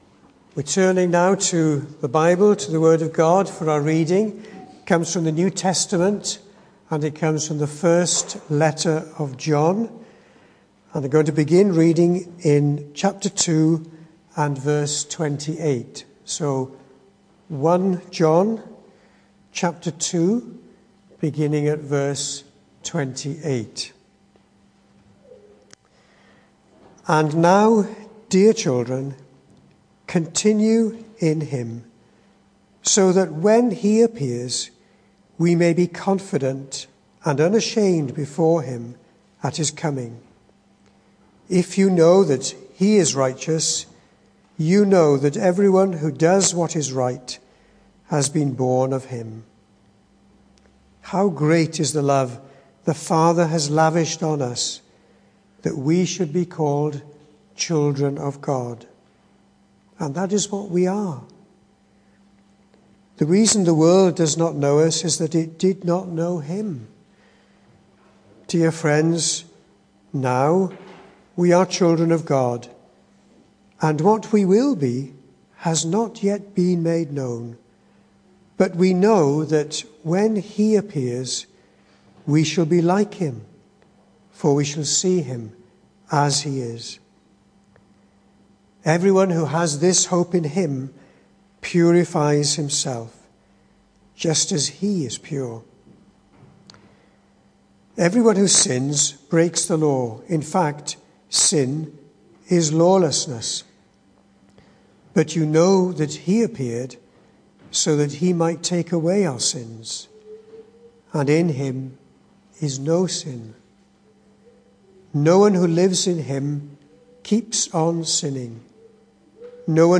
The 9th of March saw us hold our evening service from the building, with a livestream available via Facebook.